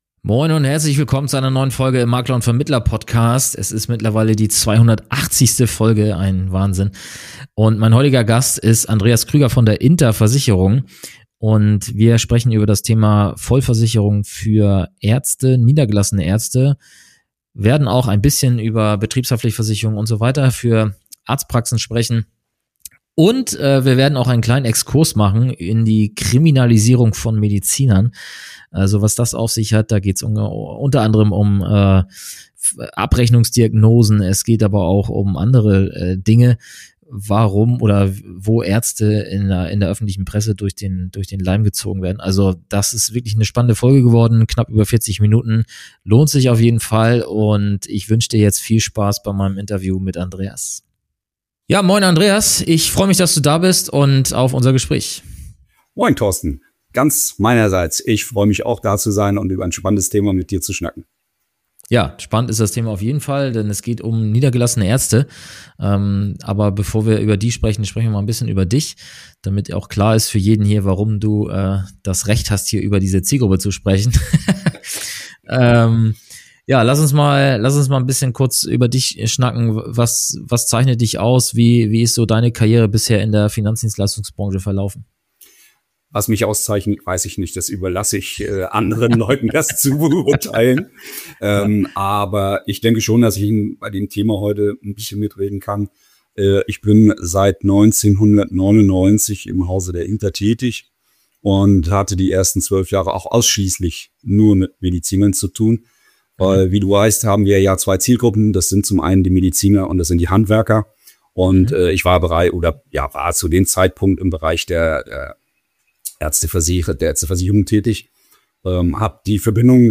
Im Gespräch erfährst du, wie Vermittler sich dieser besonderen Zielgruppe nähern, worauf Ärzte und Zahnärzte bei Berufshaftpflicht, Rechtsschutz und Krankenversicherung achten sollten und welche Lösungen die INTER speziell anbietet.